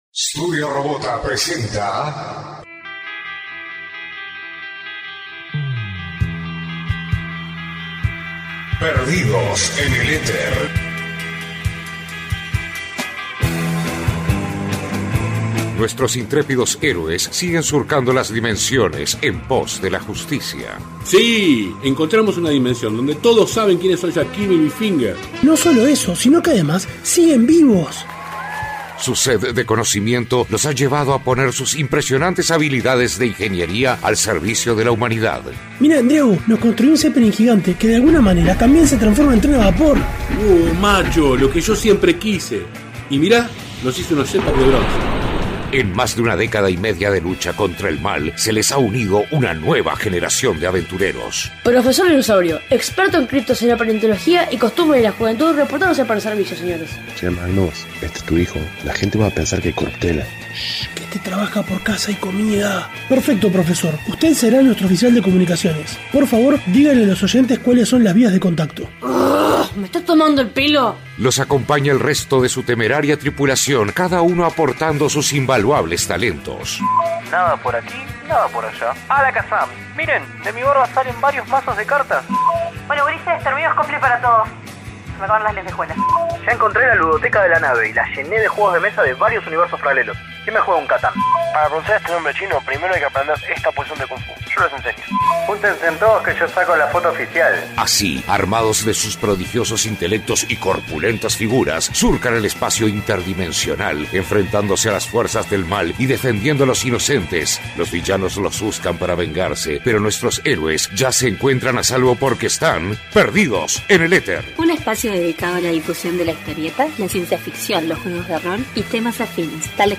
y grabado en territorio argentino.